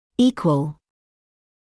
[‘iːkwəl][‘иːкуэл]равный, одинаковый